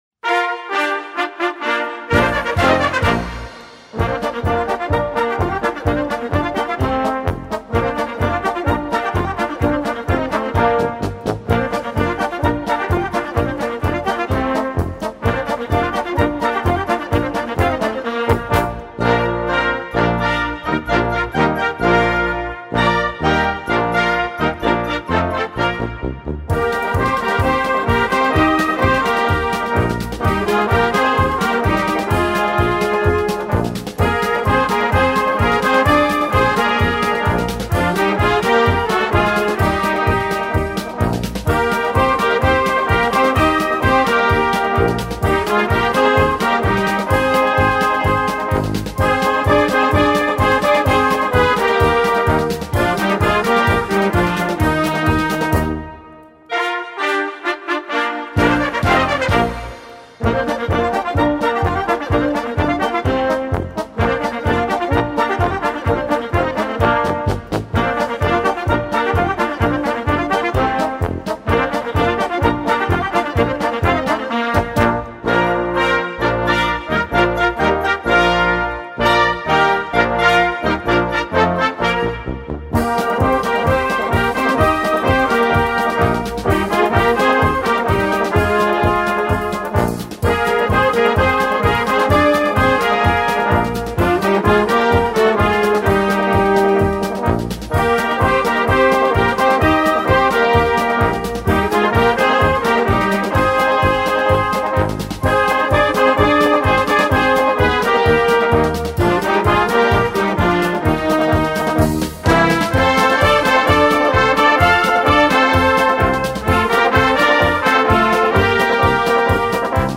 wind ensemble
The typical happy sound